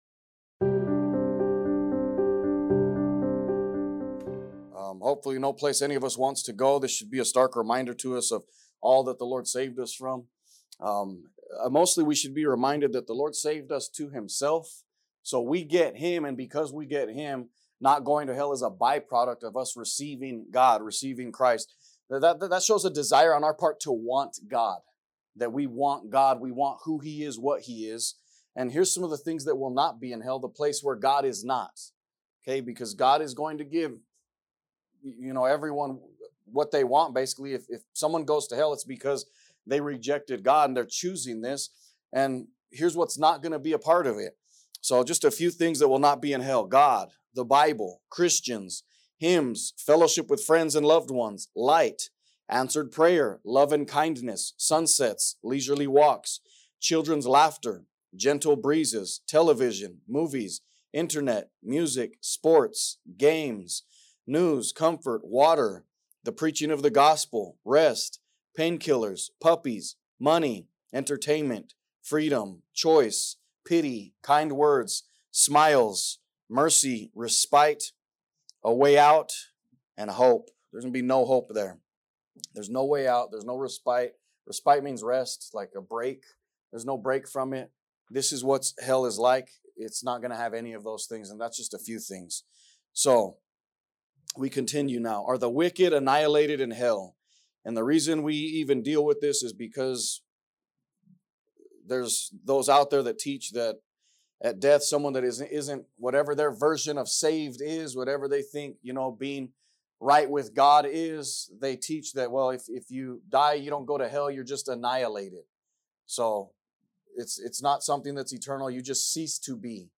A message from the series "The Great Commission."